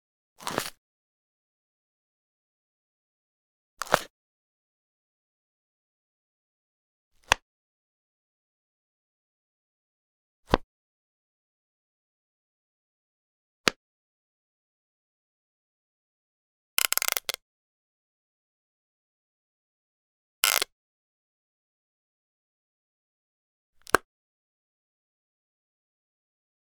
Hinged Ring Box Open Lid Sound
household